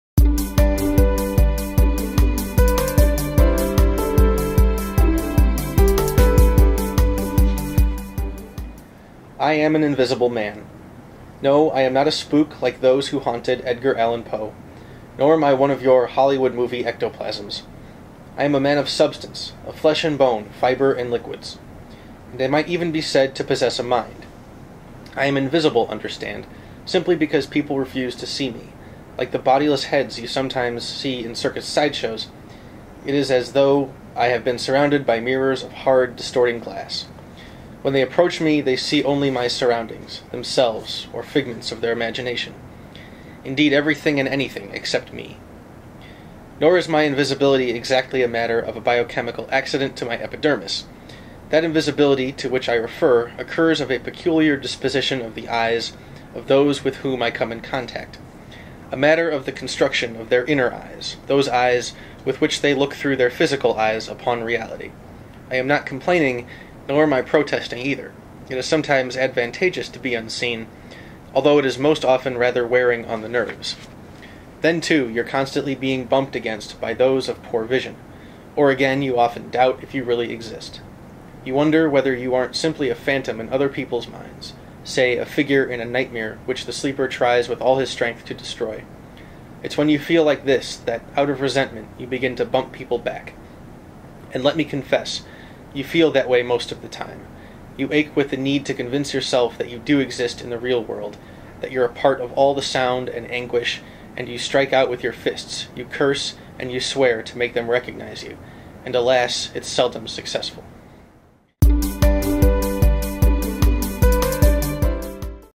A reading from Ralph Ellison's INVISIBLE MAN